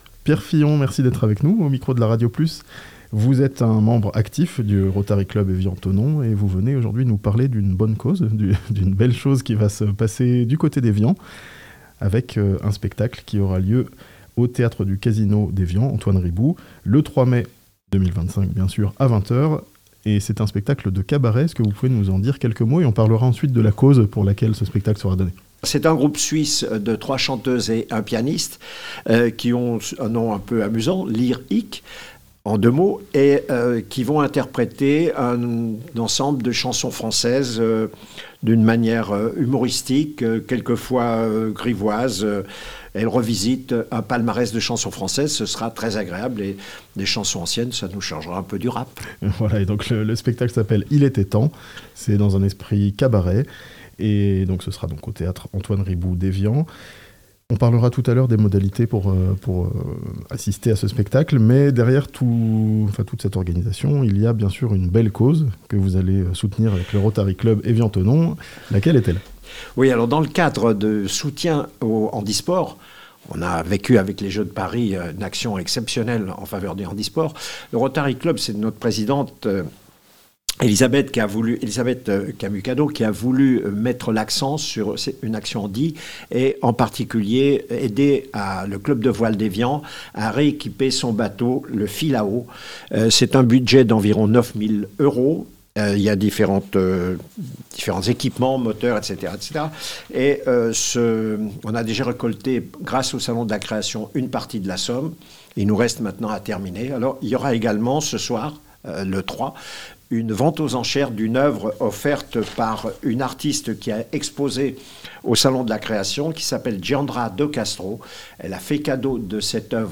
A Evian, un spectacle de cabaret au profit de la handi-voile (interview)